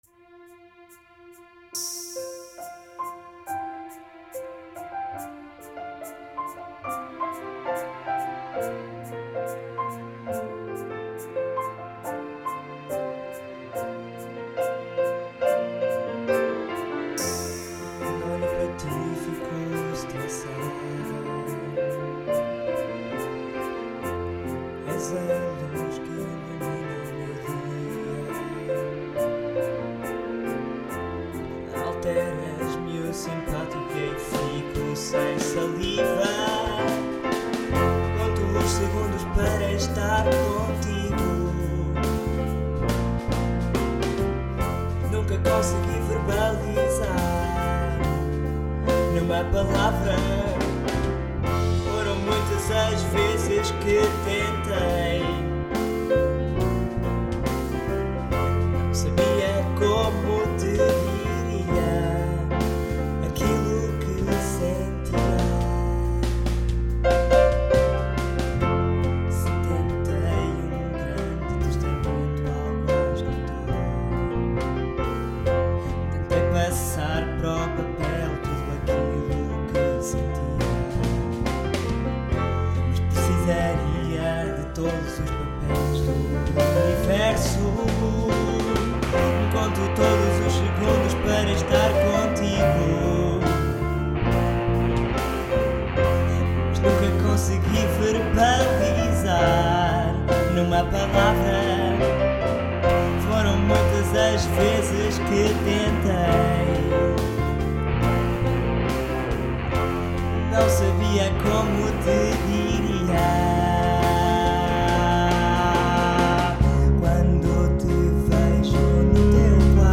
But I really don't understand a lot about EQ's and mastering and such, I just wanted to give it a more professional sound, it sounds kind of artificial, I don't know.. so what do you think I could do about it?
I recorded the piano and voice with mic, guitar with amplitube, the drums are pre-made loops, and the rest are just samples that I played from midi keyboard.